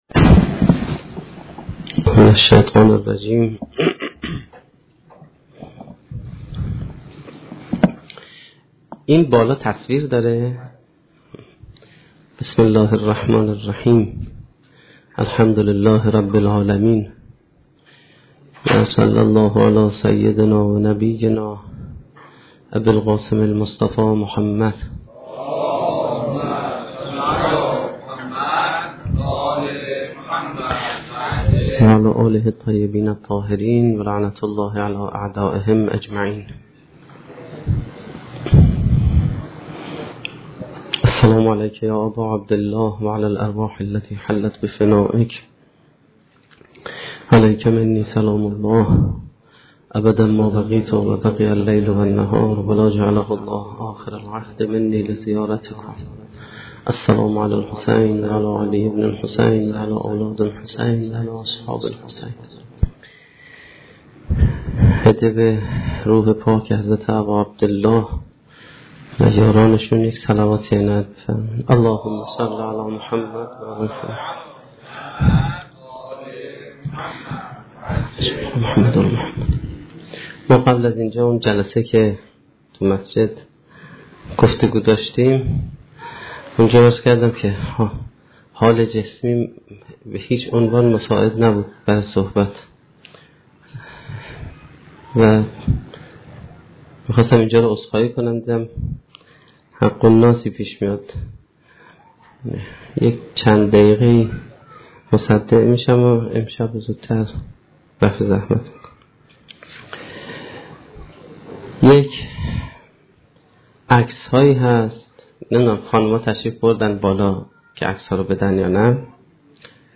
سخنرانی شب 24 محرم1435-1392